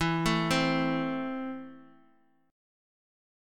Esus4#5 chord